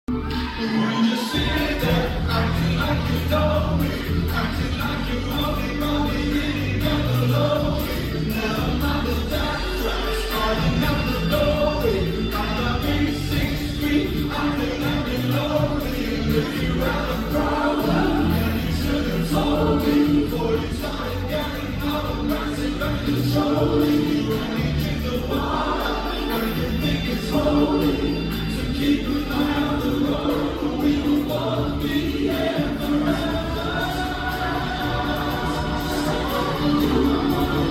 Pardon my fangirling through this.